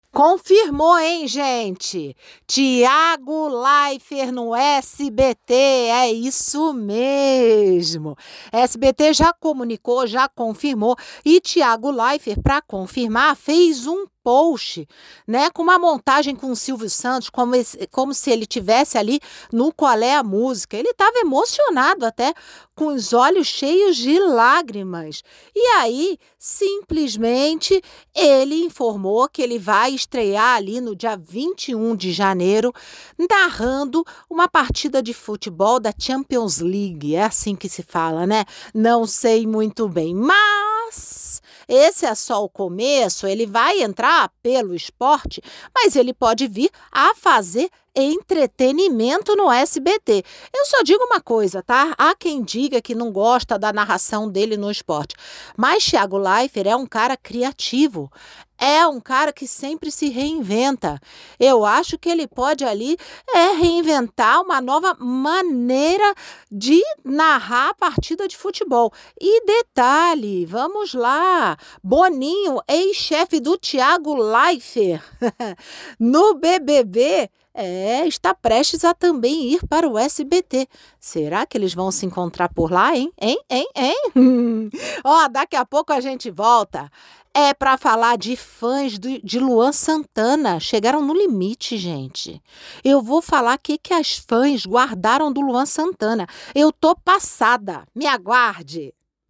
Como uma boa conversa, sempre com opinião e debate, a coluna incentiva o ouvinte a interagir, afinal, fofoca boa é fofoca comentada por várias pessoas.
Categoria: Coluna
Periodicidade: 5 vezes por semana (segunda a sexta-feira), em 3 edições, gravada